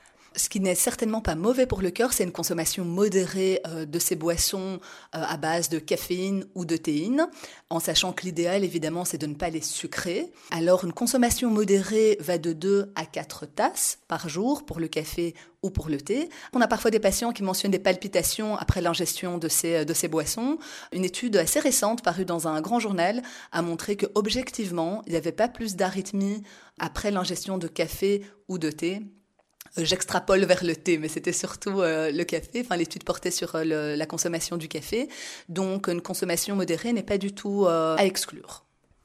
Et à propos, le café: stop ou encore? Écoutez ici un extrait de cette rencontre portant précisément sur la question du café: